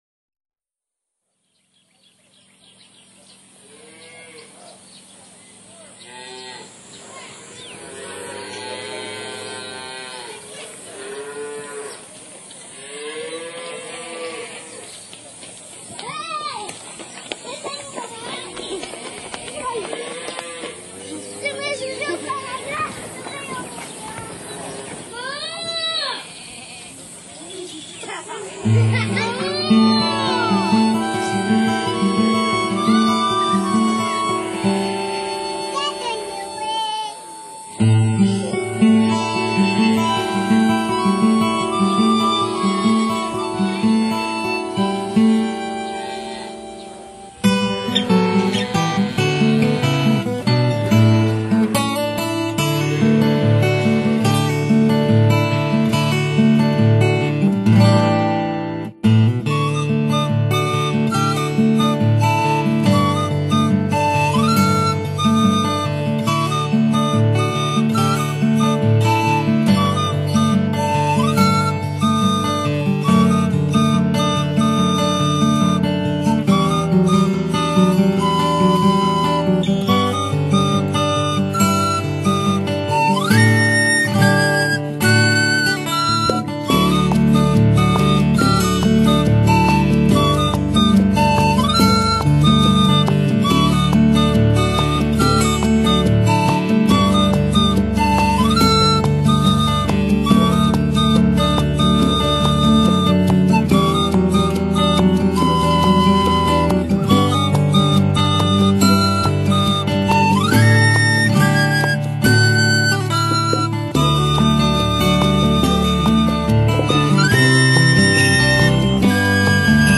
孩子的争吵自然的响声、再加上初鹿牧场的牛叫，以及一堆“中古”美少女带着孩子出游的景象。